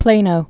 (plānō)